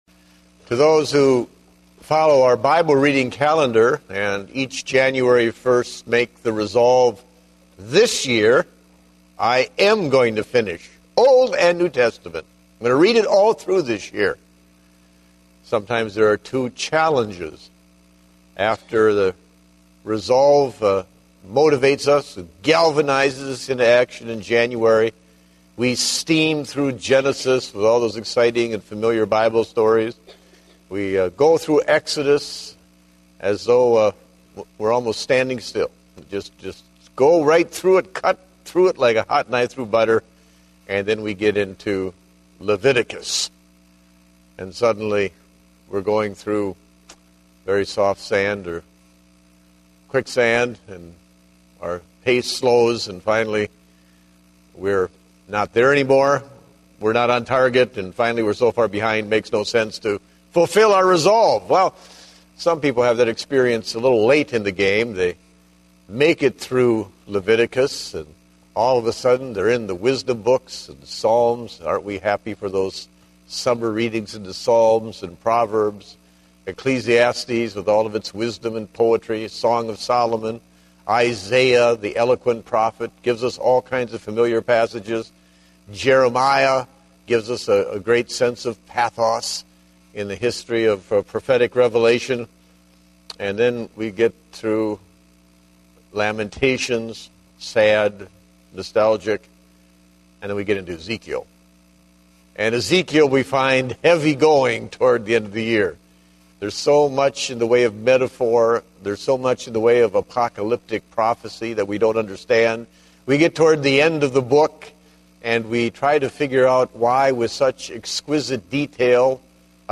Date: December 5, 2010 (Evening Service)